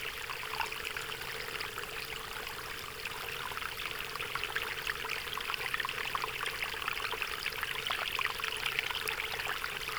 st_waterloop1.wav